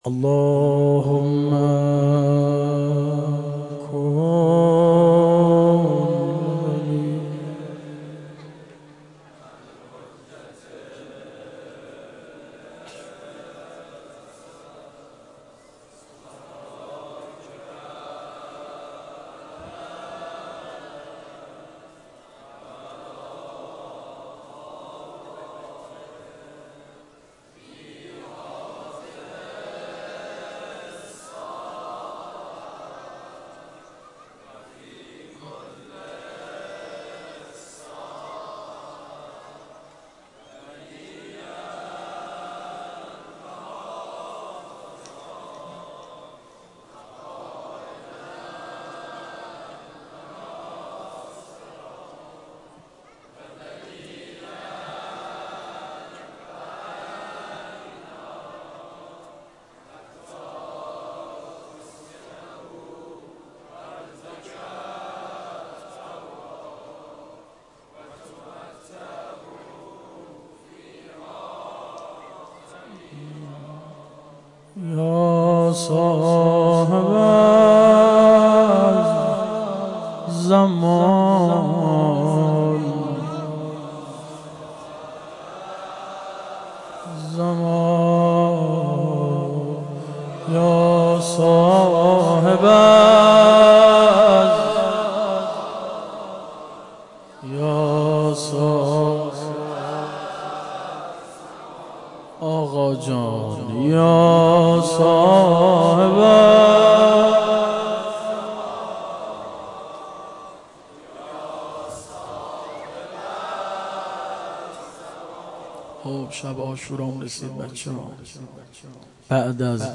روضه شب عاشورا98